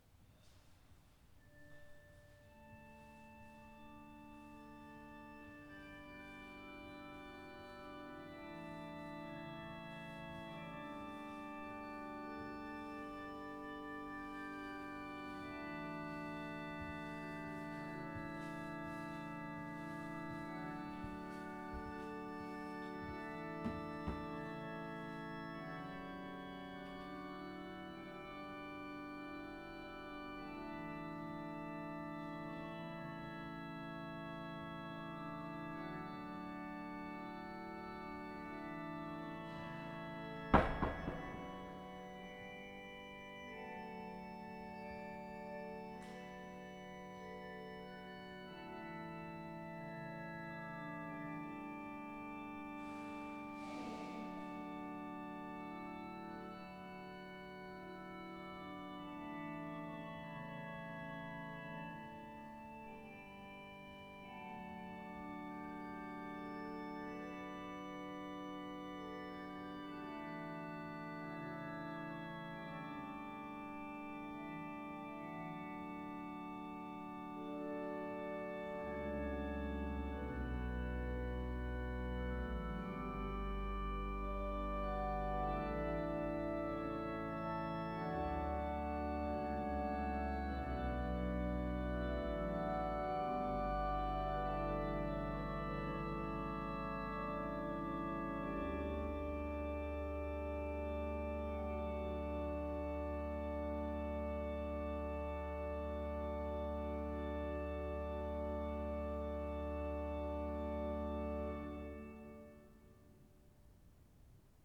Bodø domkirke
Improv 65 - Lyd og stemmeprøve Kort improvisasjon over Gje meg handa di.  (Åpen kirke så noe støy)